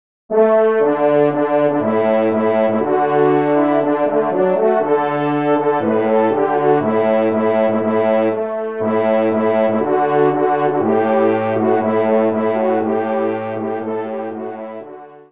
Pupitre 3° Trompe